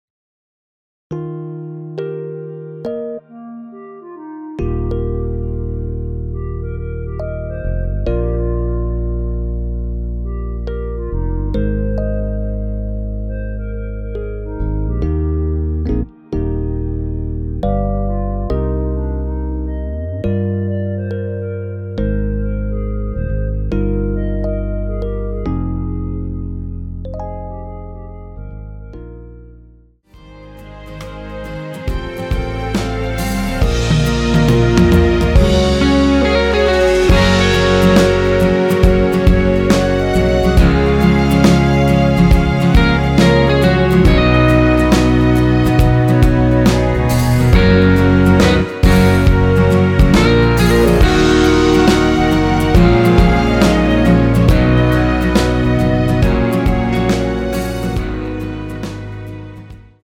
전주 없이 시작 하는 곡이라 전주 1마디 만들어 놓았습니다.(미리듣기 참조)
원키에서(-7)내린 멜로디 포함된 MR입니다.
Eb
앞부분30초, 뒷부분30초씩 편집해서 올려 드리고 있습니다.